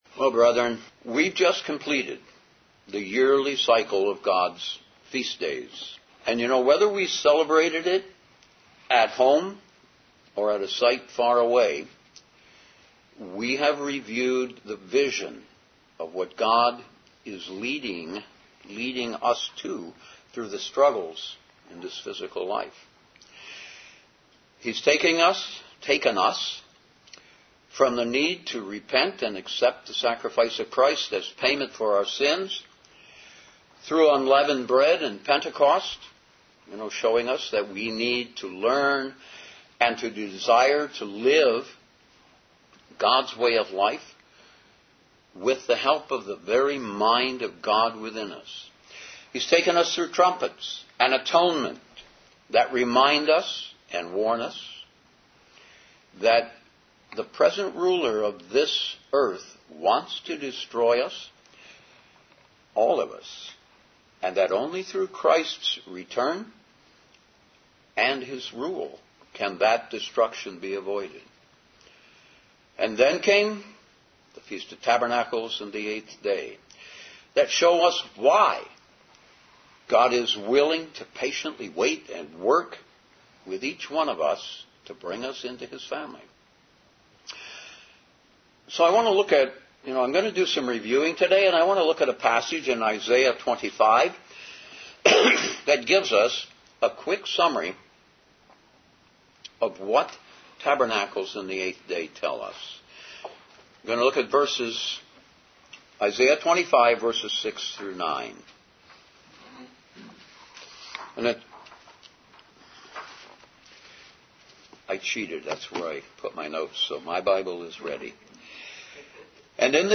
Given in Detroit, MI